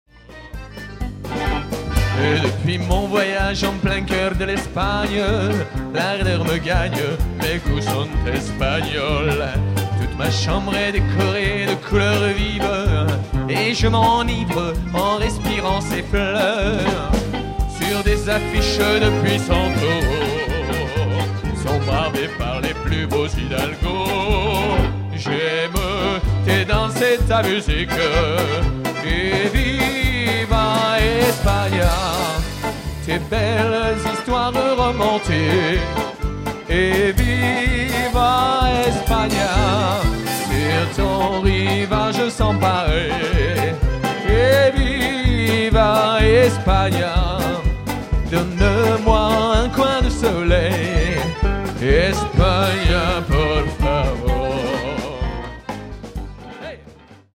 paso